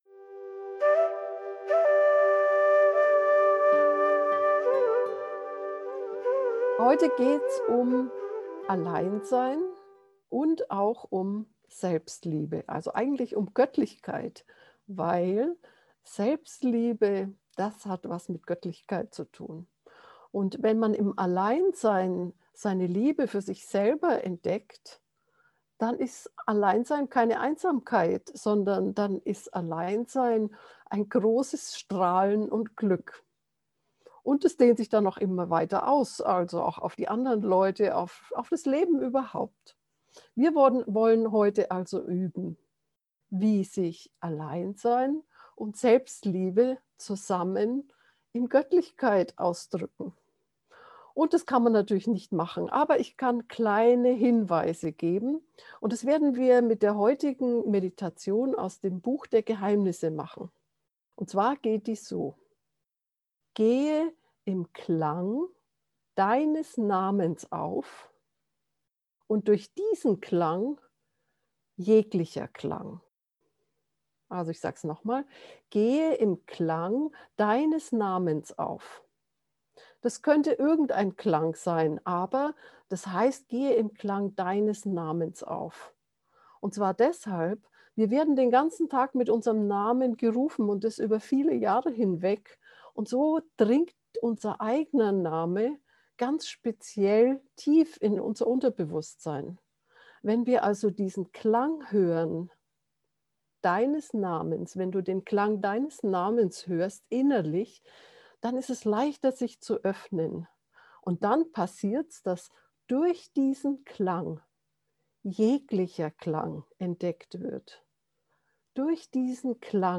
alleinsein-selbstliebe-gefuehrte-meditation